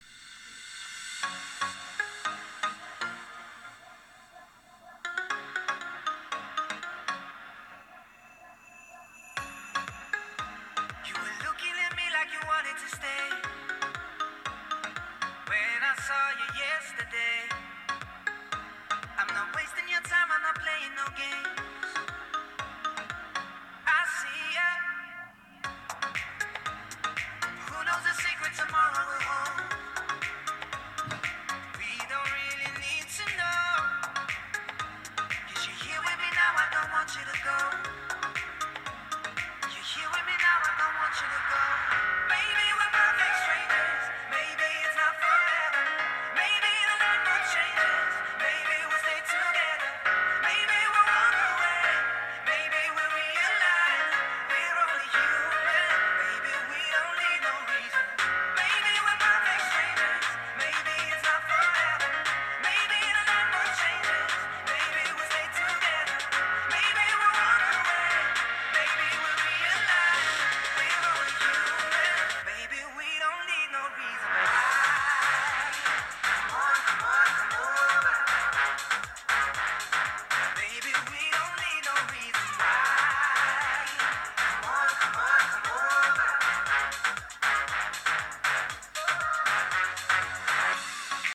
参考までに、ヘッドホンから聞こえるサウンドを録音したデータを掲載する。
▼ヘッドホンにマイクを近接させて録音。
※あくまで低音・高音の強さをなんとなく分かってもらうためのものなので要注意。実際には、よりクリアで響きの良いサウンドが耳に届けられる。